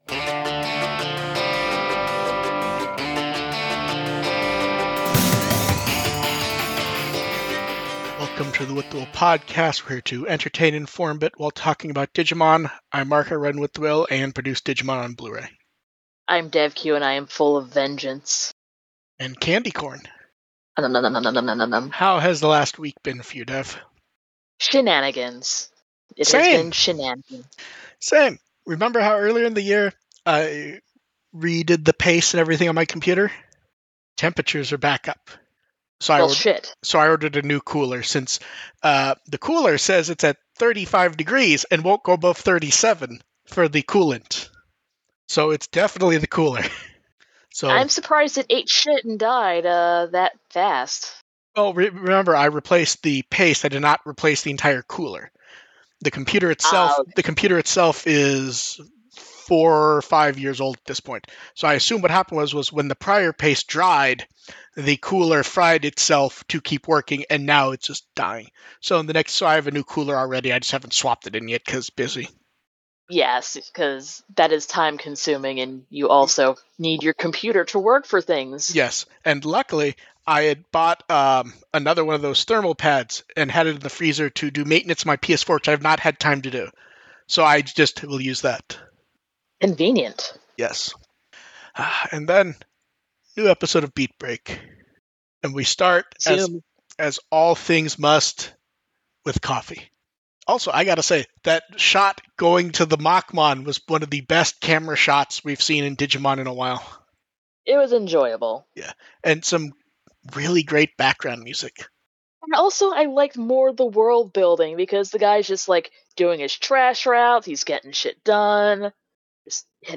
The podcast audio is the livestream clipped out, with an intro and outtro added, along with some tweaking to try and improve audio quality.